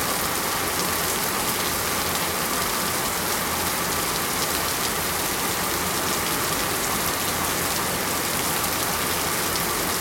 sounds_rain_heavy_01.ogg